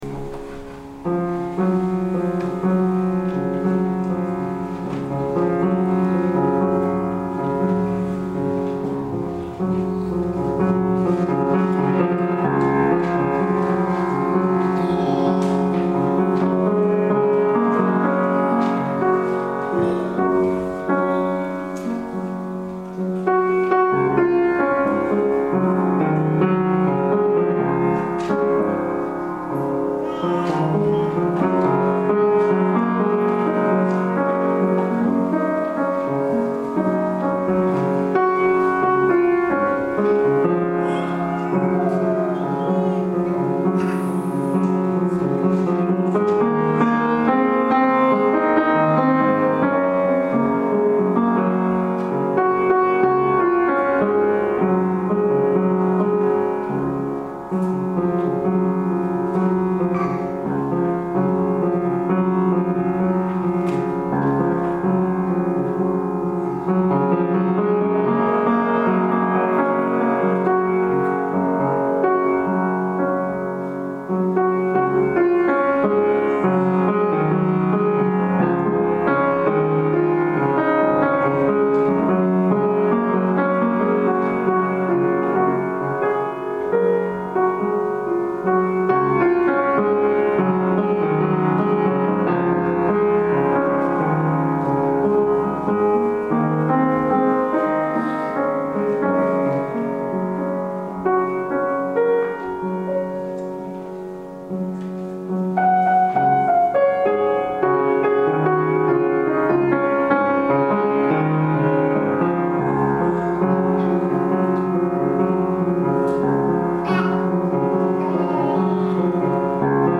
Halloween Transformation Sermon (audio and text)
A sermon for Halloween: “Trying on a New Face”.